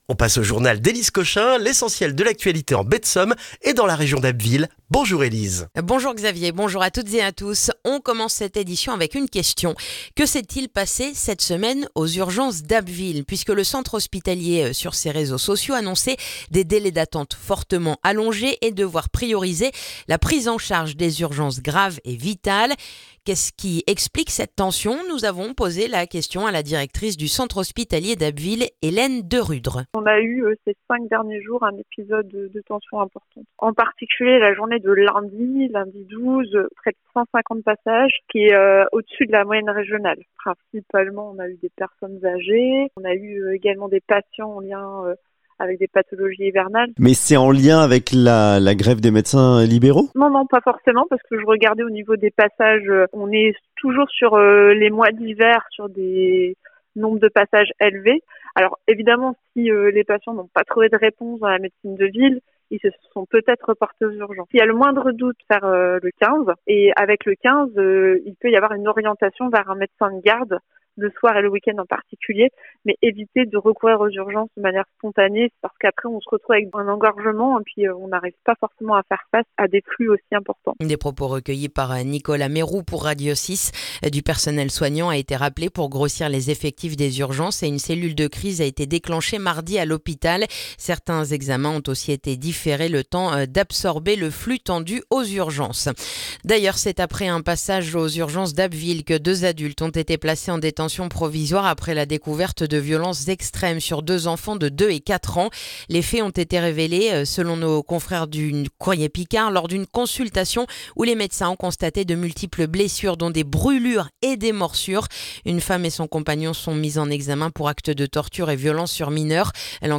Le journal du vendredi 16 janvier en Baie de Somme et dans la région d'Abbeville